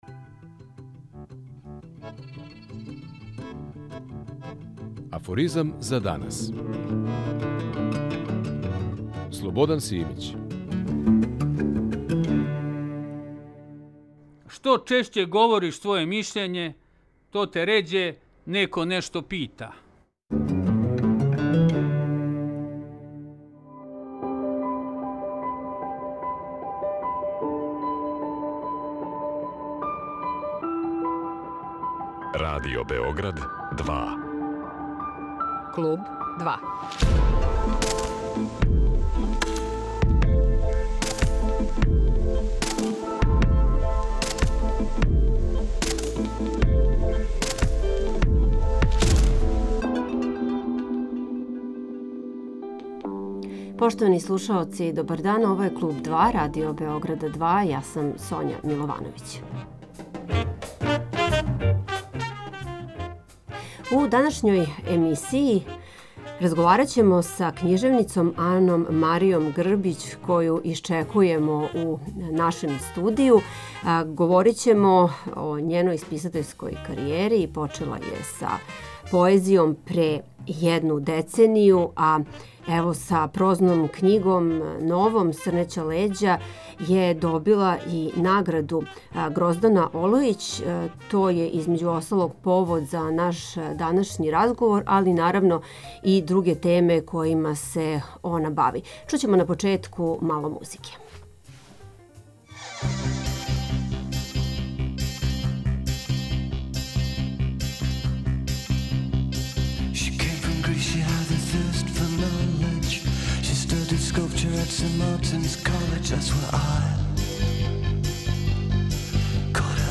О везама поезије и прозе, додирима са стваралаштвом Гроздане Олујић, раду на будућем роману, књижевној сцени... чућете такође у нашем данашњем разговору.